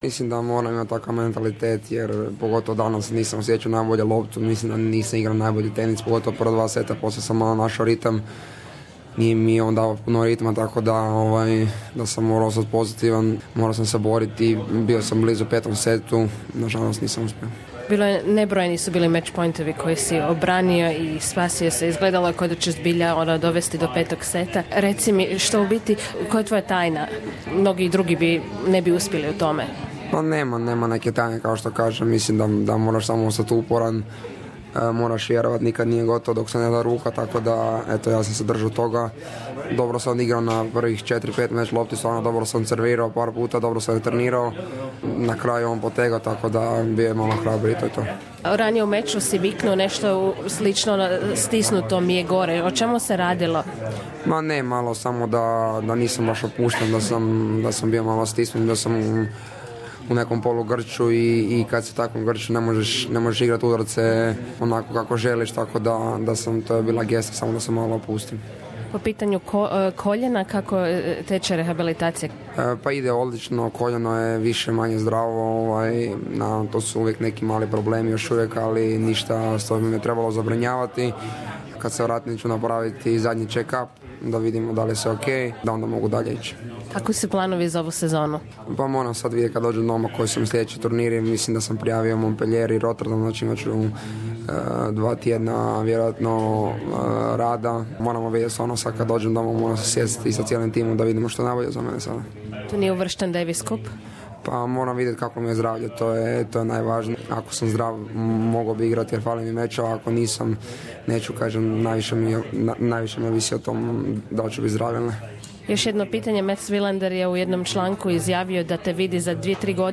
Hrvatski tenisač Borna Čorić komentira za program na hrvatskom jeziku radija SBS svoj meč na početku turnira Australian Open i govori o svojim planovima za ovu 2017 godinu